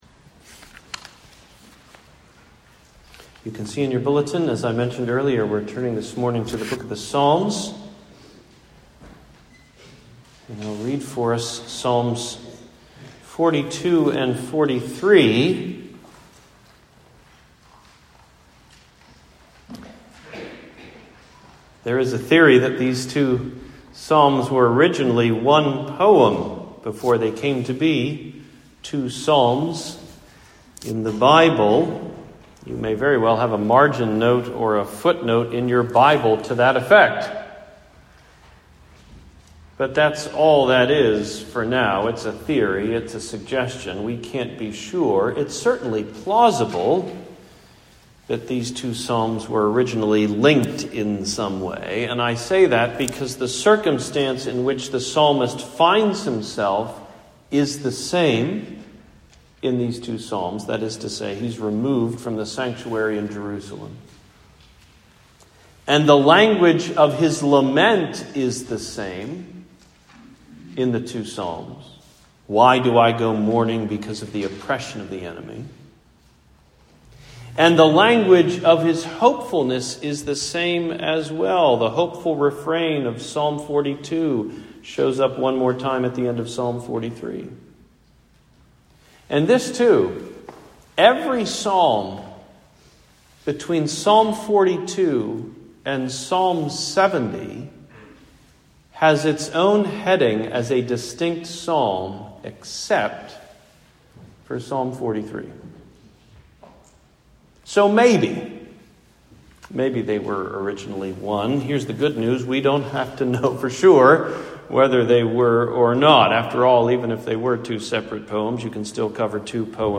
Why Cast Down?: Sermon on Psalms 42-43